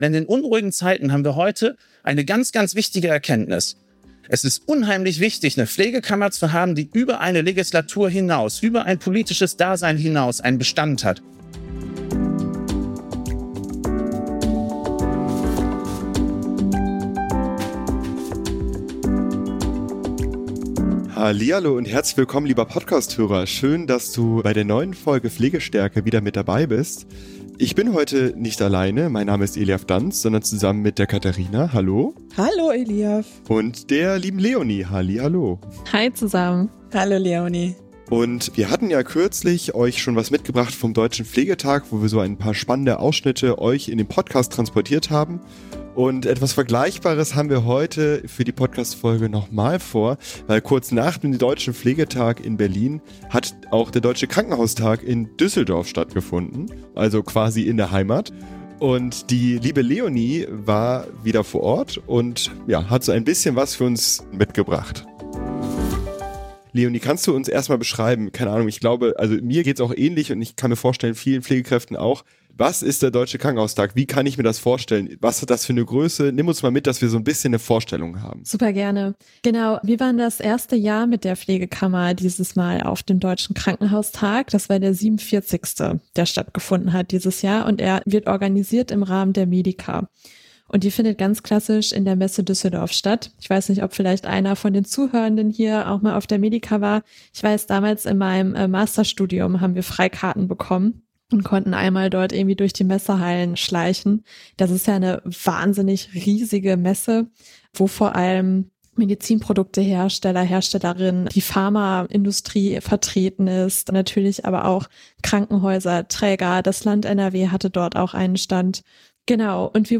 In dieser Sonderfolge vom Deutschen Krankenhaustag